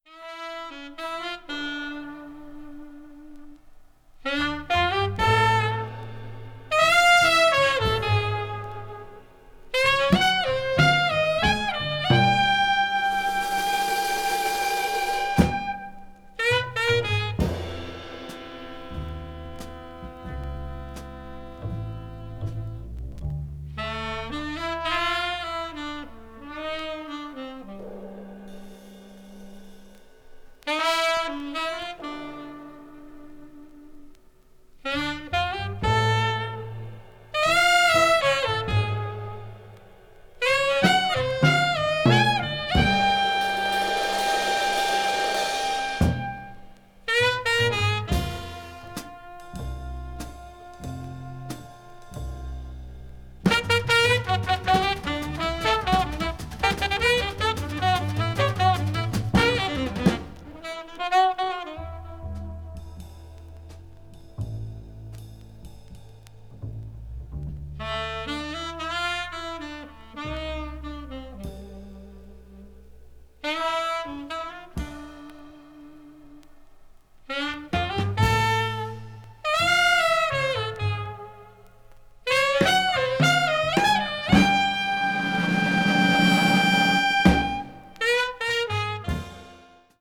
avant-jazz   free jazz   post bop